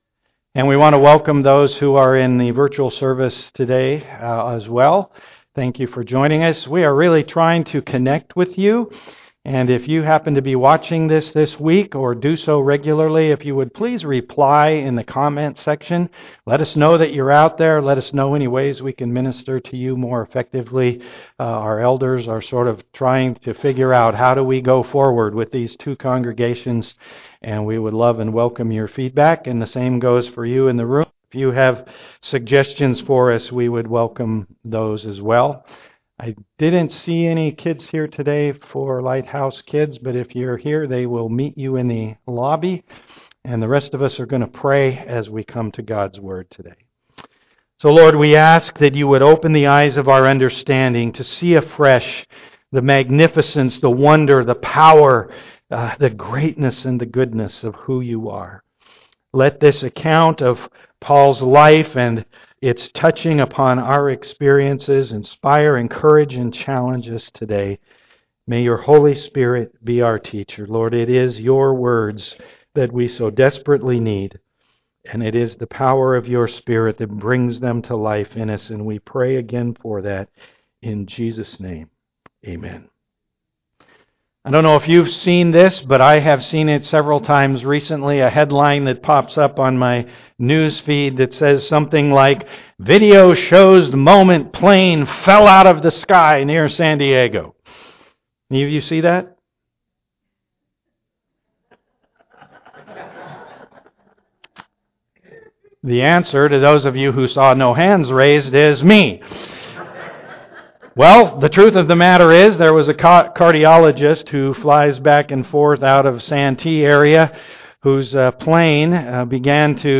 Acts 27:1-44 Service Type: am worship Any crashes in your life?